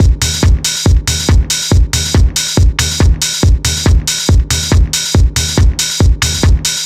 NRG 4 On The Floor 009.wav